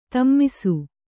♪ tammisu